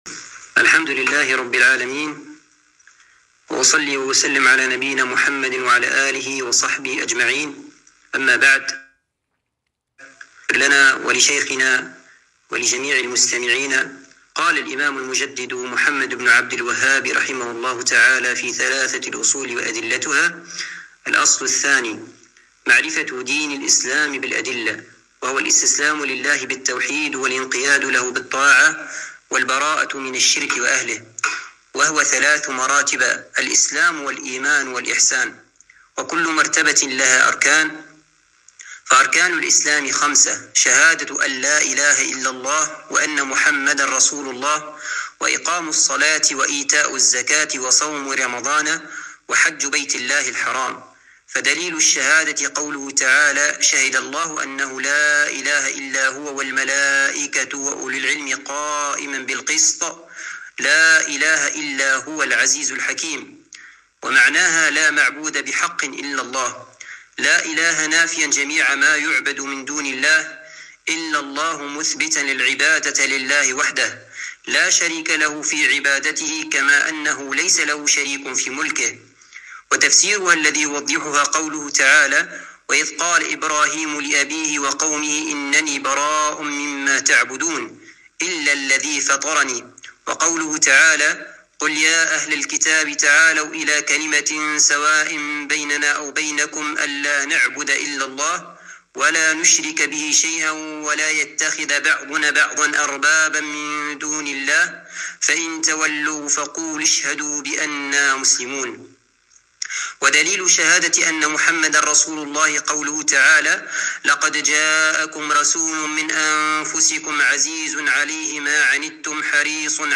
الدرس الرابع - شرح ثلاثة الأصول